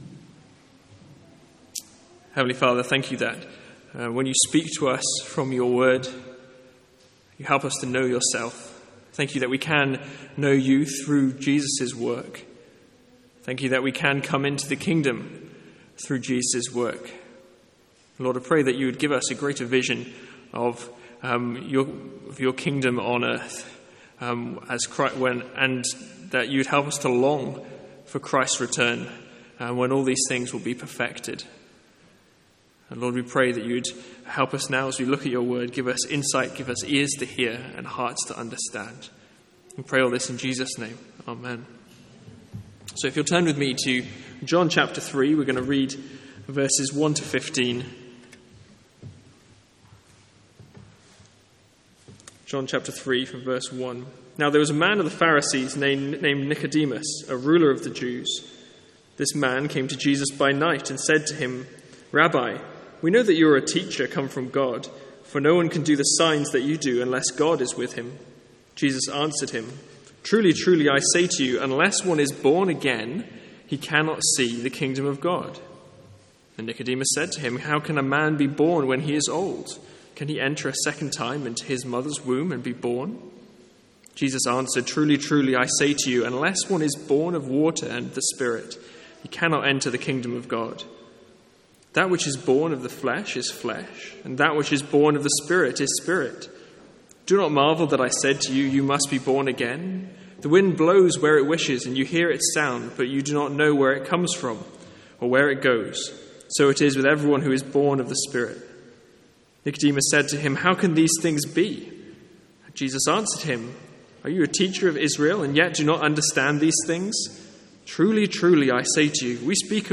Sermons | St Andrews Free Church
From our evening series in the Lord's Prayer.